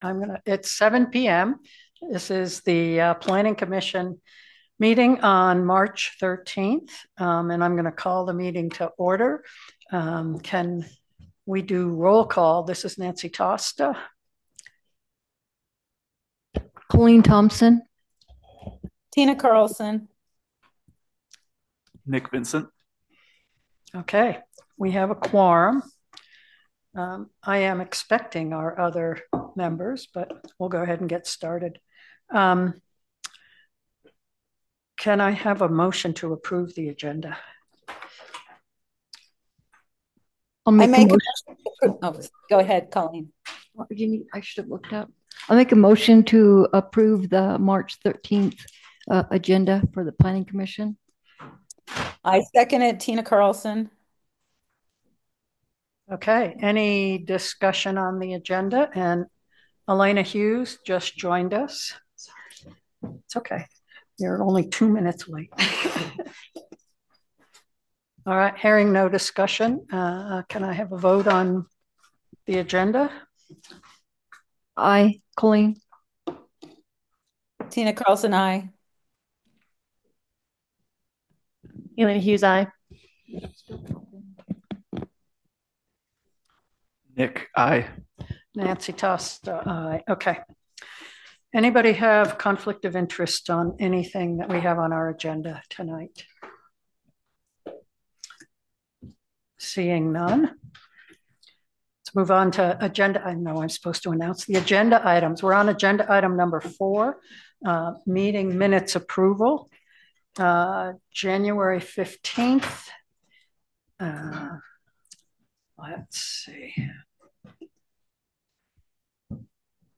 The Boulder Town Planning Commission will hold a regular meeting on Thursday, March 13, starting at 7:00 pm at the Boulder Community Center Meeting Room, 351 No 100 East, Boulder, UT. Zoom connection will also be available.